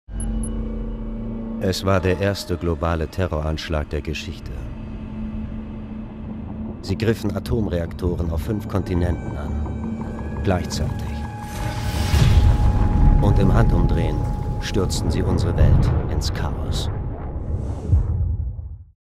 Sprecherdemos
Gaming: Abenteuer Spiel
Call-of-Duty-Advanced-Warfare_Story-Trailer.mp3